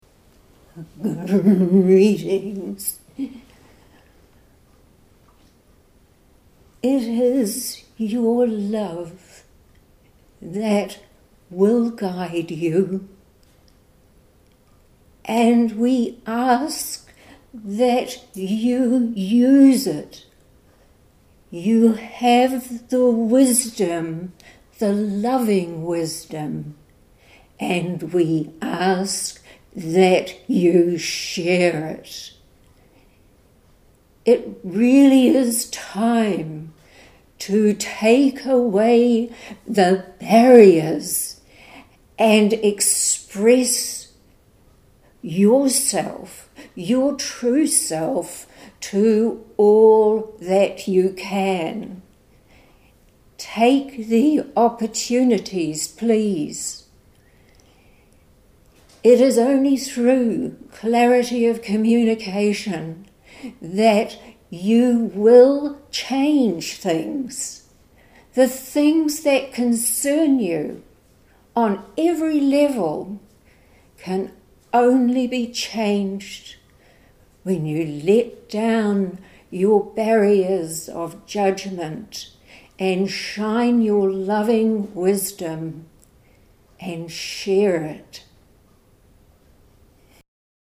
at our weekly meditation group.